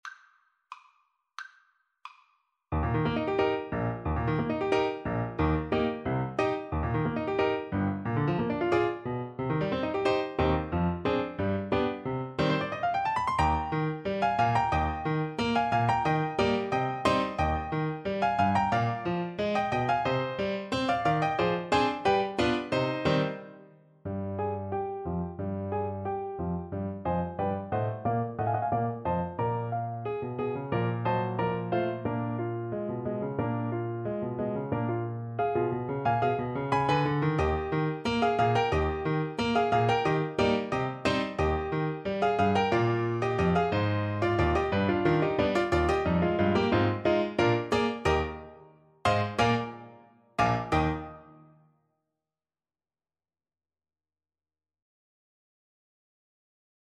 Play (or use space bar on your keyboard) Pause Music Playalong - Piano Accompaniment Playalong Band Accompaniment not yet available transpose reset tempo print settings full screen
Eb major (Sounding Pitch) C major (Alto Saxophone in Eb) (View more Eb major Music for Saxophone )
~ = 100 Allegro giocoso, ma non troppo vivace =90 (View more music marked Allegro giocoso)
Classical (View more Classical Saxophone Music)